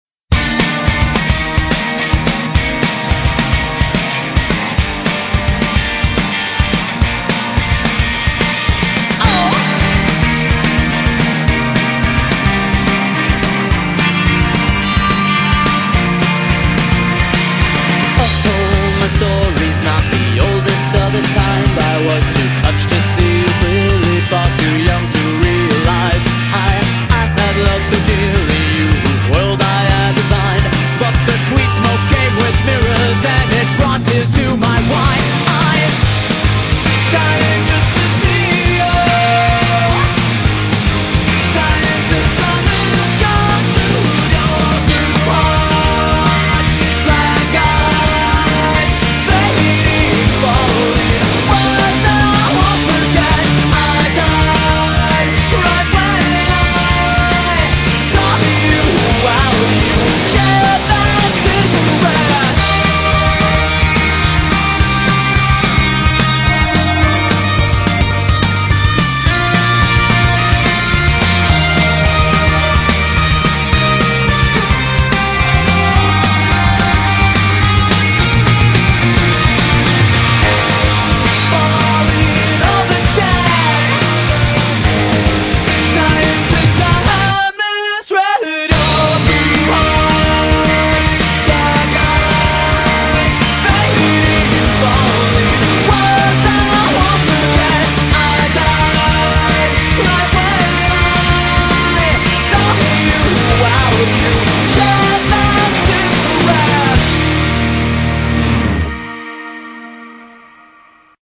BPM215-217
Audio QualityMusic Cut